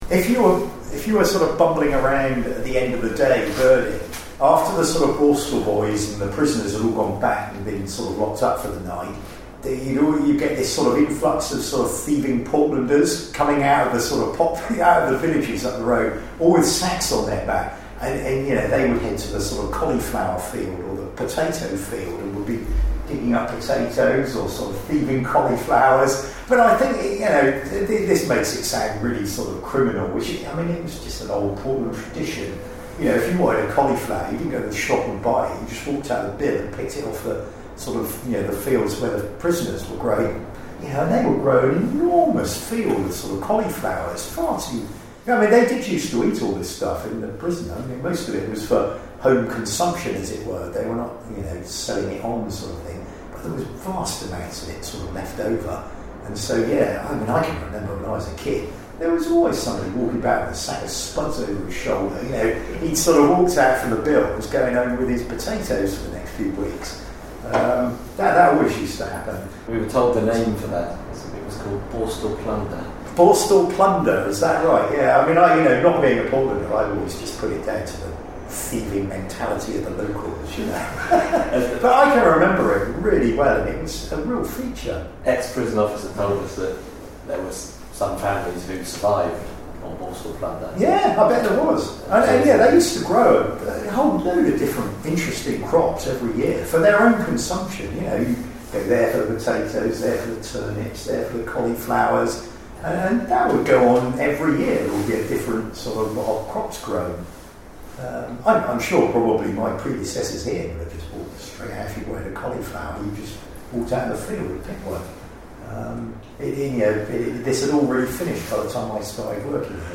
recorded these anecdotes, memories and experiences of Portland people.